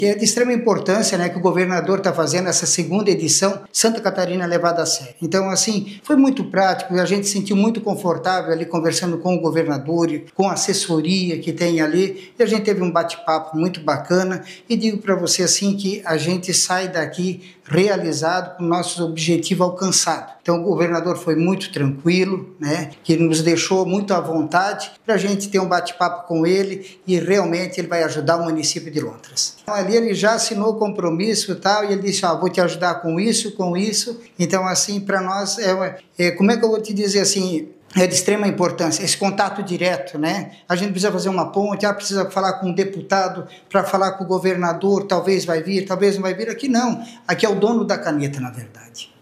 Após o encontro individual com o governador Jorginho Mello, o prefeito de Lontras, Rubens Roberto dos Santos, ressaltou os investimentos que terá em parceria com o Estado:
SECOM-Sonora-SC-Levada-a-Serio-Prefeito-Lontras.mp3